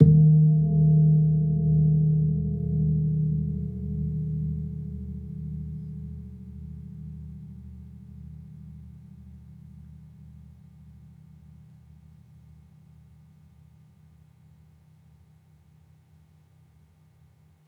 Gong-D#2-f.wav